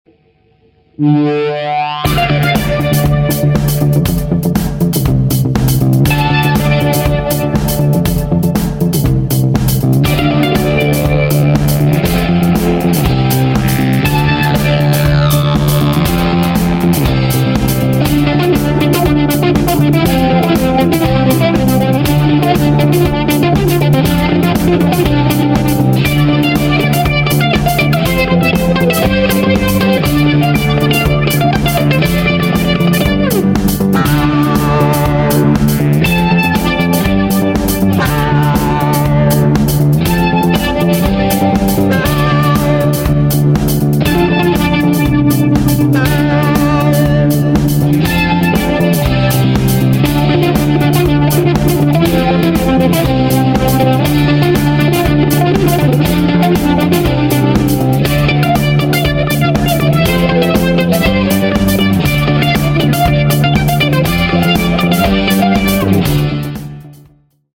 Fender Telecaster American Standard 2011,